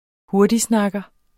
Udtale [ ˈhoɐ̯diˌsnɑgʌ ]